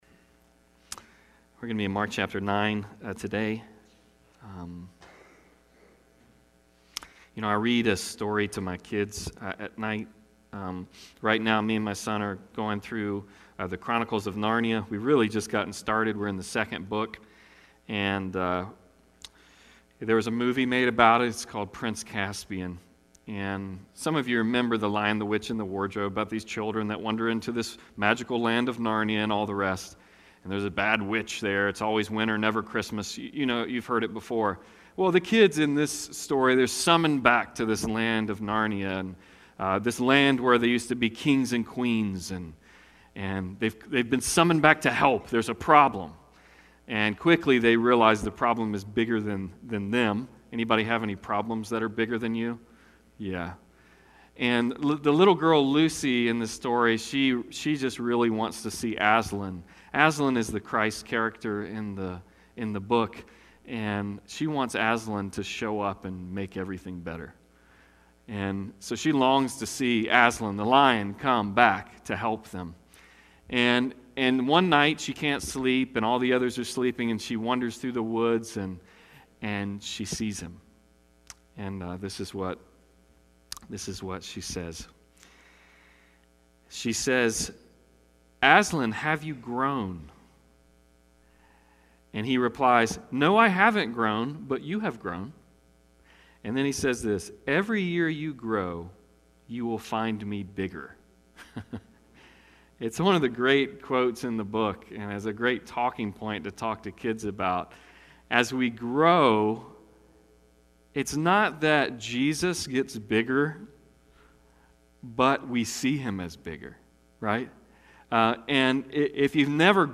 Passage: Mark 9:42-50 Service Type: Sunday Service